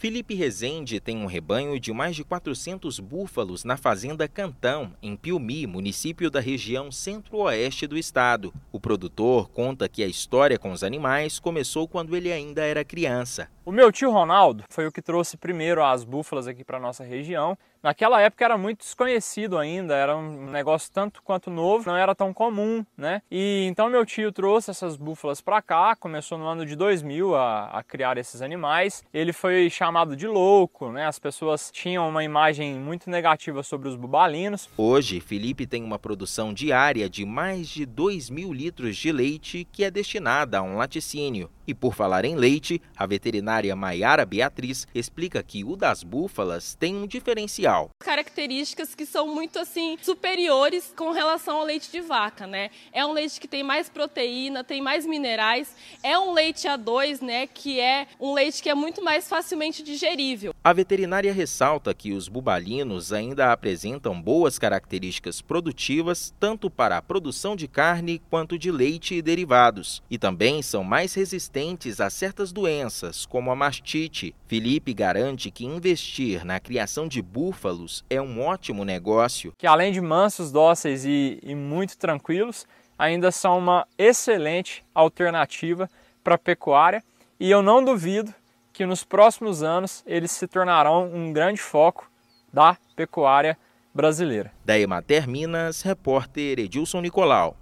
[RÁDIO] Produtor rural do Centro-Oeste de Minas é referência na criação de búfalas
Rebanho chega a mais de 400 animais, que produzem cerca de 2 mil litros de leite por dia. Ouça matéria de rádio.